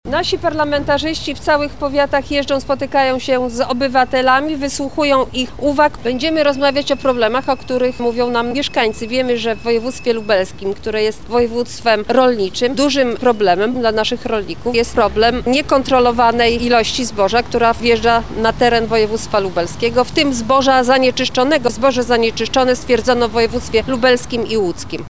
-podkreśla posłanka Marta Wcisło.